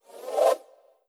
SwooshSlide2.wav